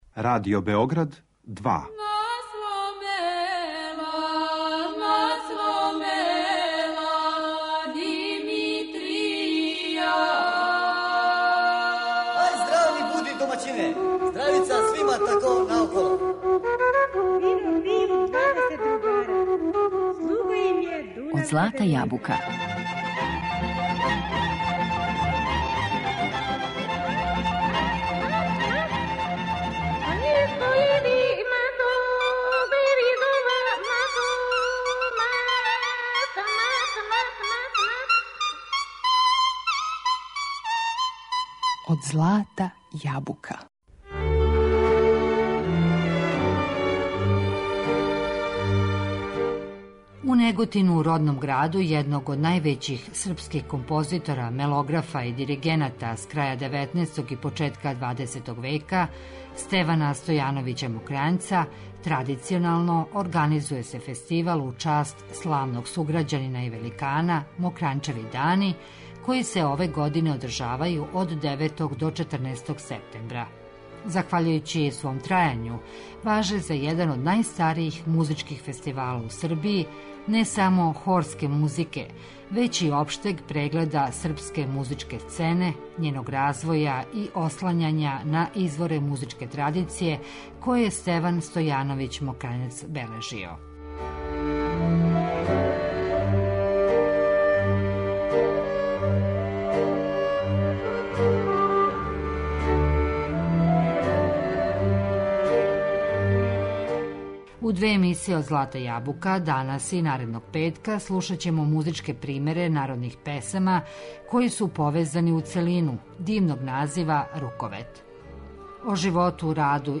Као врстан познавалац српског музичког наслеђа, саставио је "Руковети", у којима се налазе најлепше српске народне песме, а неке од њих чућете у емисијама.